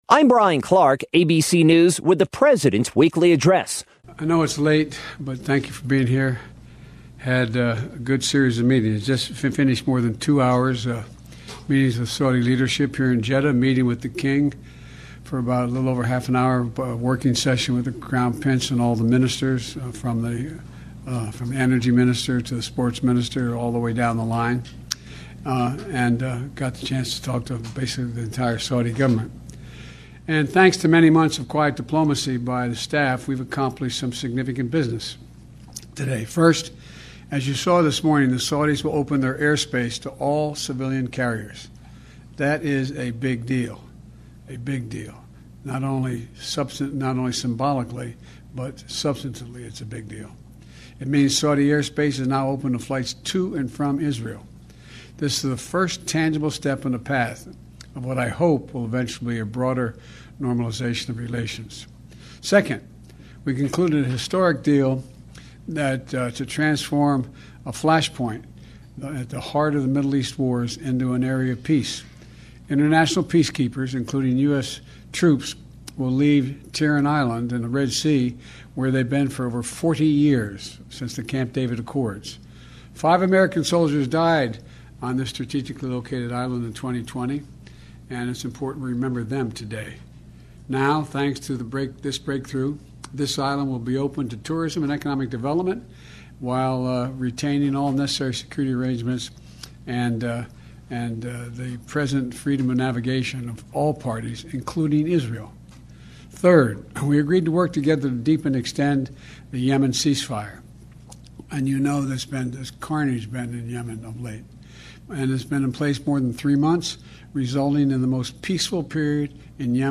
Biden was Monday’s KVML “Newsmaker of the Day”. Here are his words: